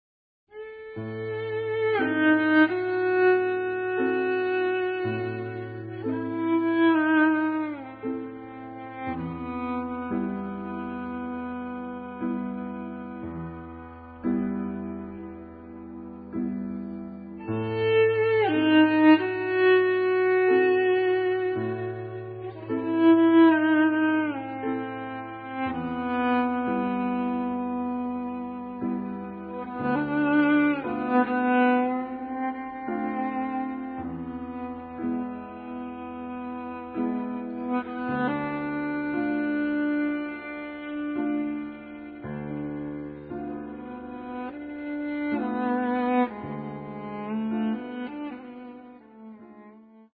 Grand Piano